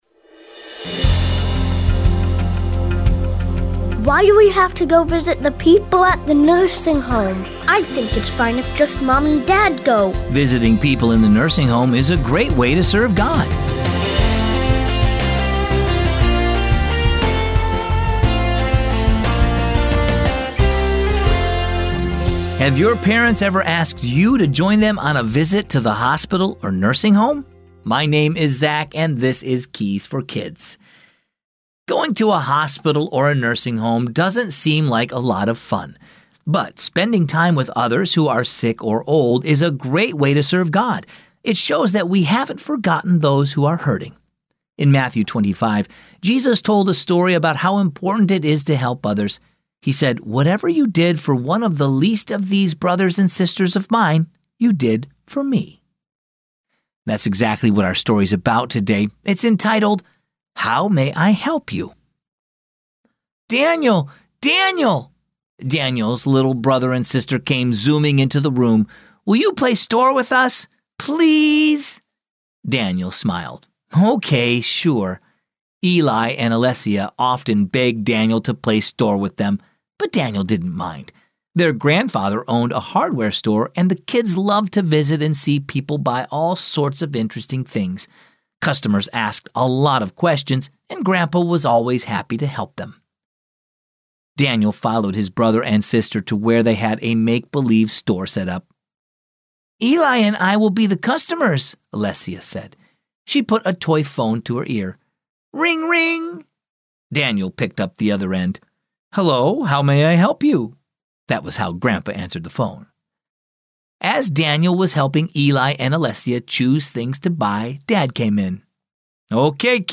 Keys for Kids is a daily storytelling show based on the Keys for Kids children's devotional.